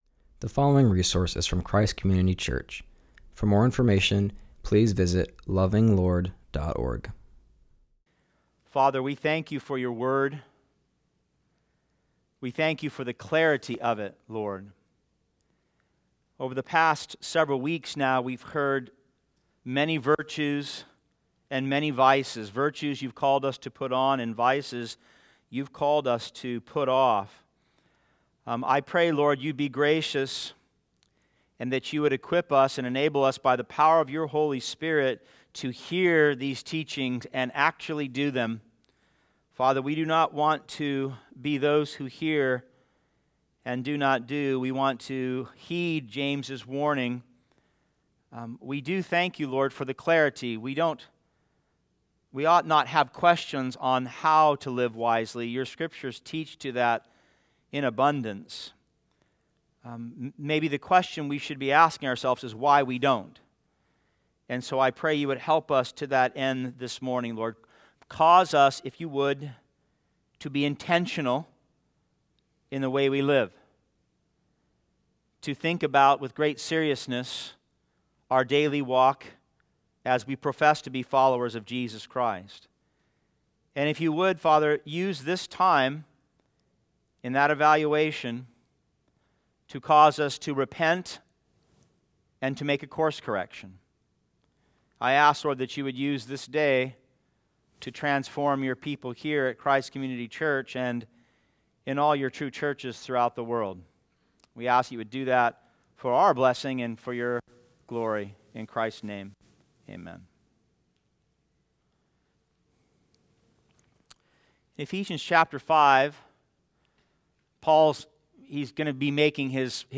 continues our series and preaches from Ephesians 5:15-17.